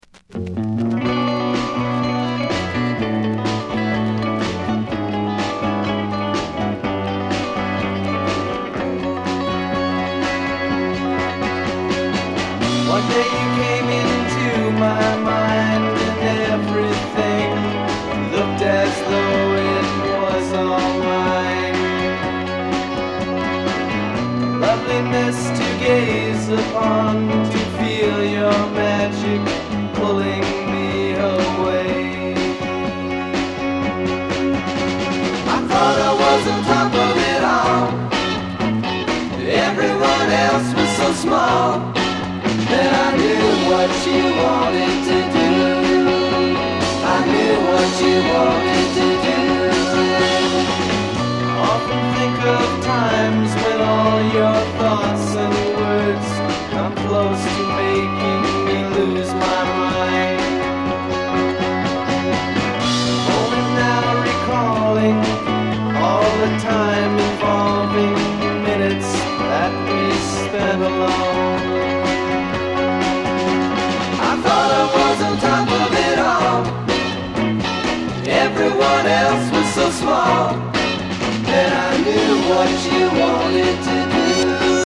西海岸フォークロックバンド